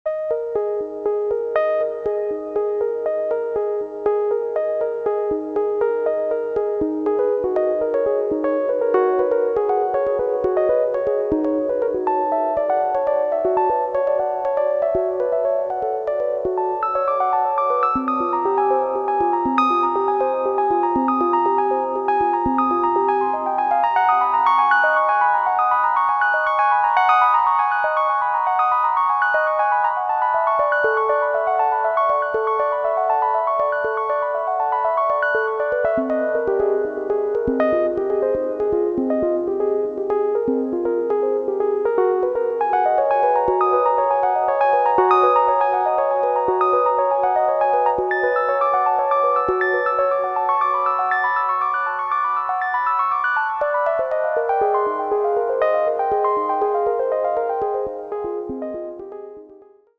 すこぶるディープで内省的なトライバル/アンビエント/クロスオーヴァーなグルーヴ。